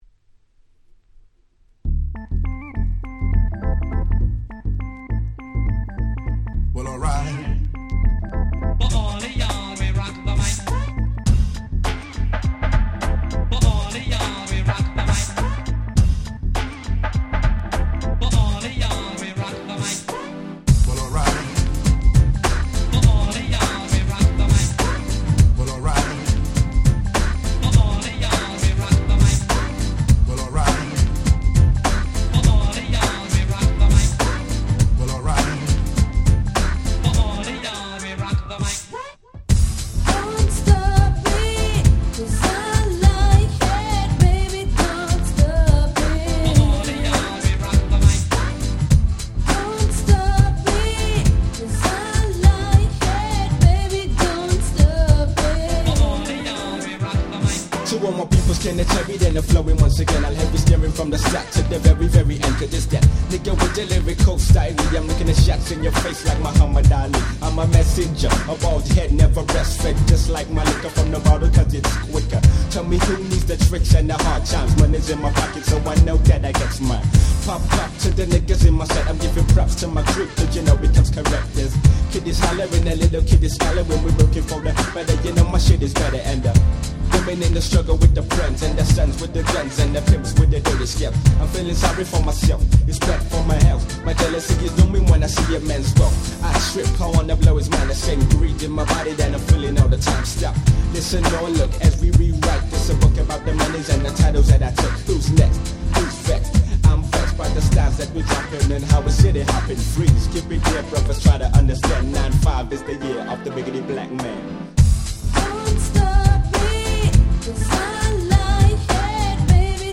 サビで女性Vocalも絡む大変キャッチーなユーロ歌ラップ物！！
Euro歌Rap最高峰。
ユーロ キャッチー系